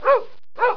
جلوه های صوتی
دانلود صدای حیوانات جنگلی 80 از ساعد نیوز با لینک مستقیم و کیفیت بالا